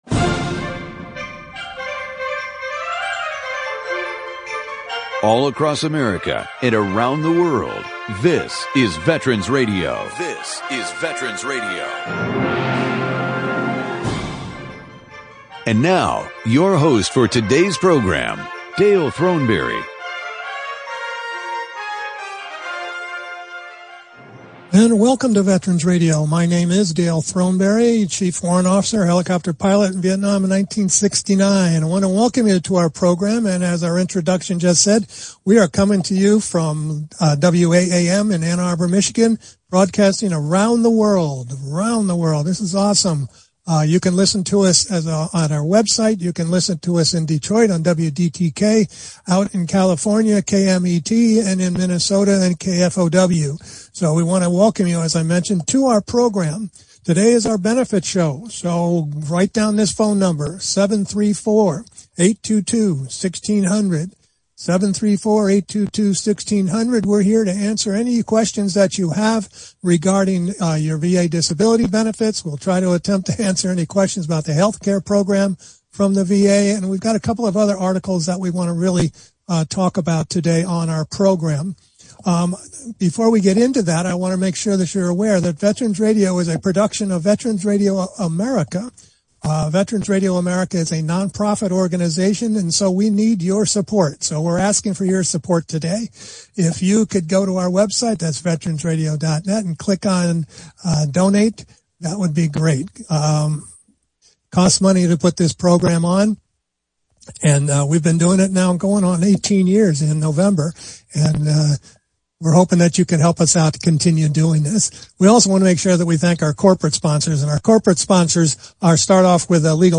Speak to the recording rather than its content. Call in during the program to ask your question or offer a comment.